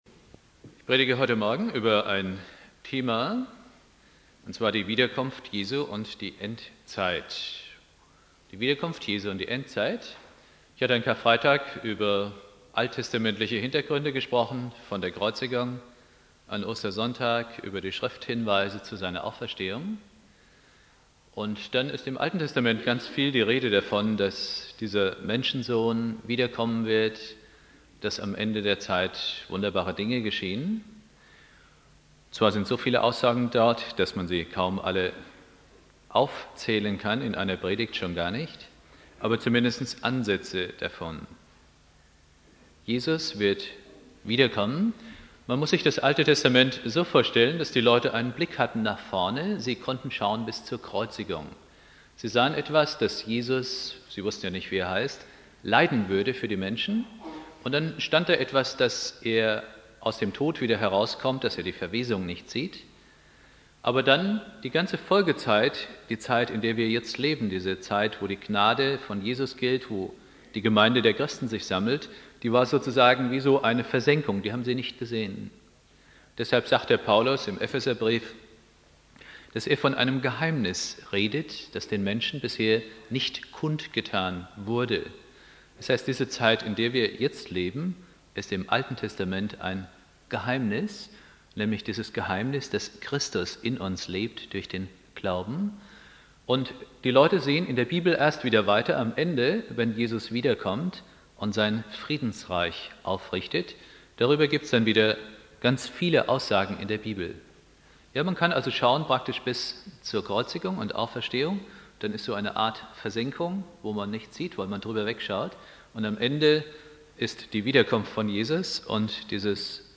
Predigt
Ostermontag Prediger